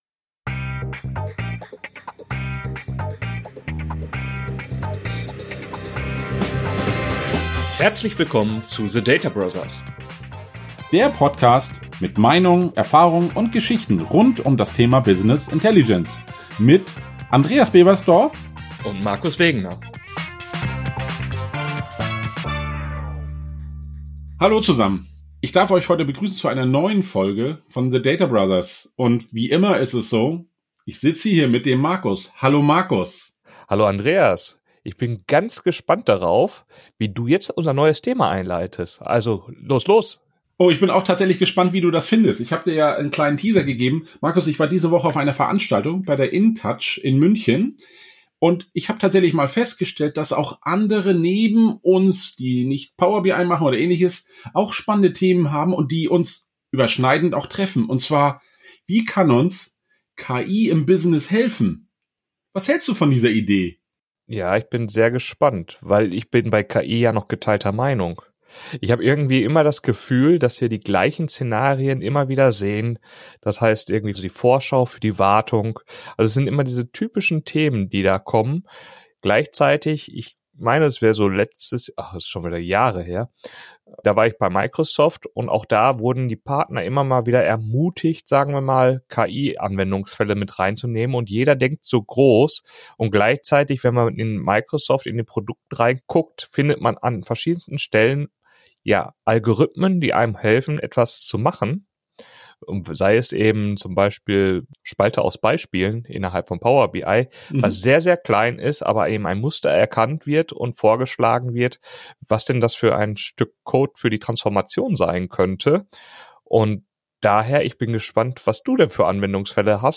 führen ein spannendes Gespräch